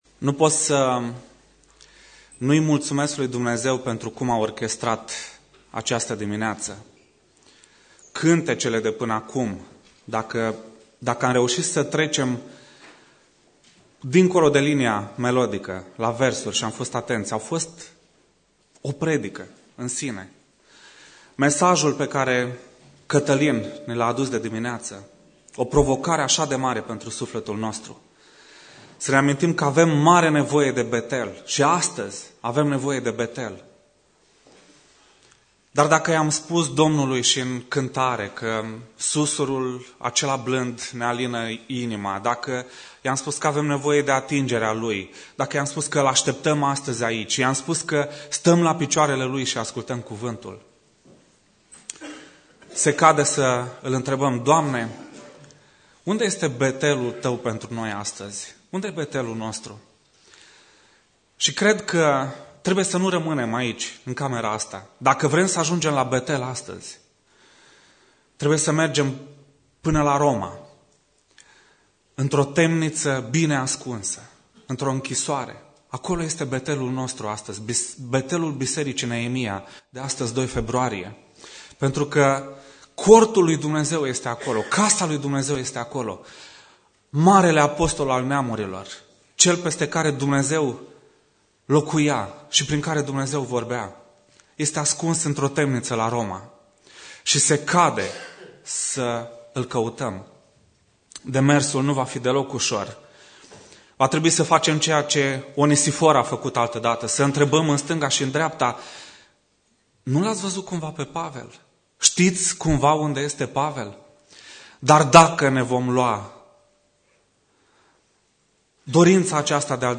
Predica Exegeza 2 Timotei 2.1-2.15